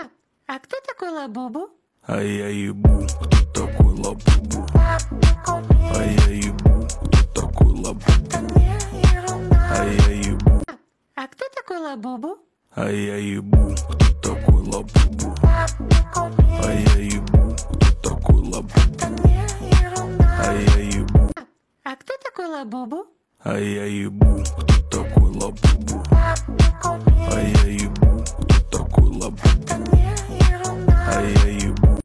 В песне происходит диалог между пацаном и взрослым.